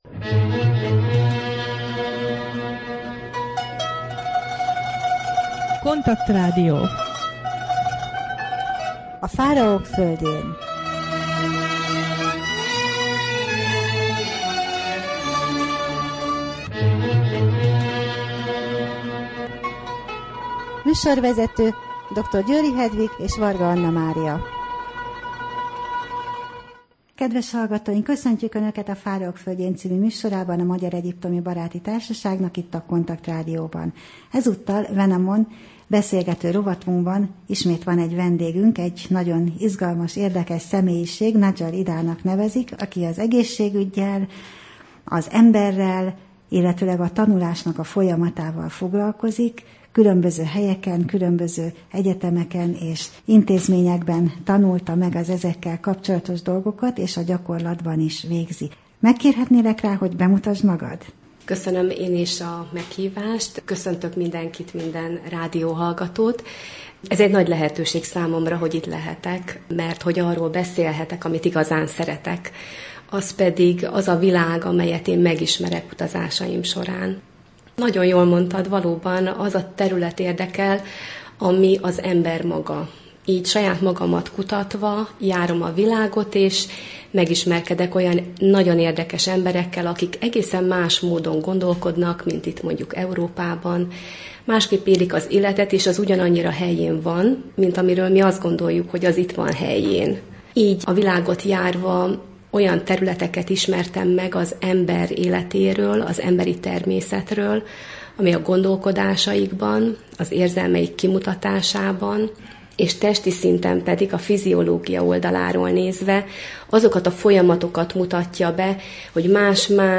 Rádió: Fáraók földjén Adás dátuma: 2013, June 28 Wenamon beszélgető rovat / KONTAKT Rádió (87,6 MHz) 2013. június 28. A műsor témája Mai téma: hajvizsgálat, nyomelemek és mérgek a szervezetben, betegségek, táplálkozás, paróka, hajfestés, mumifikálás, Beethowen, Napóleon, arzén, ón, ólom, uránium, mintavétel, gizai piramisok, Fajum, ásatás, Wadi el Jarf kikötője, Merrer naplója, Hufu / Kheopsz.